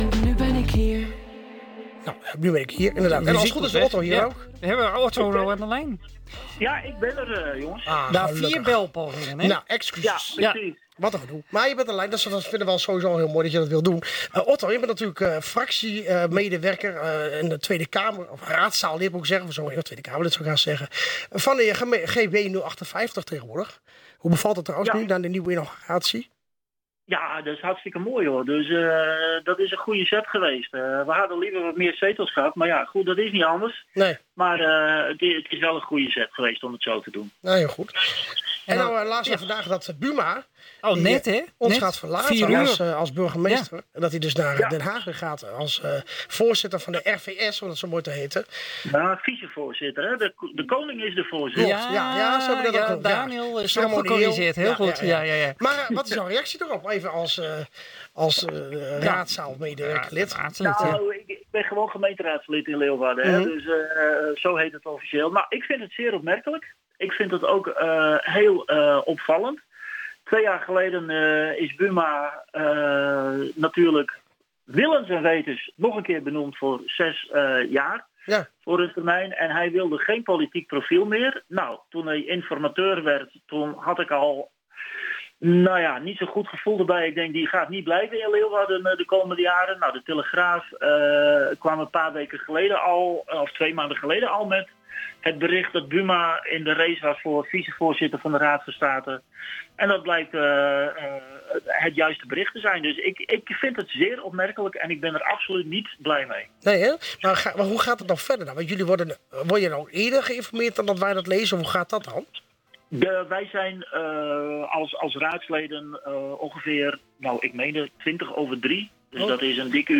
in het radioprogramma Spotlight gebeld